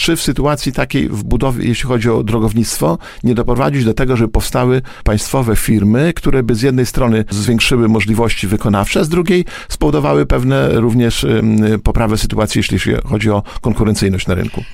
Mamy problem ze znalezieniem wykonawców inwestycji drogowych – mówił w Poranku Siódma9, starosta łomżyński Lech Szabłowski.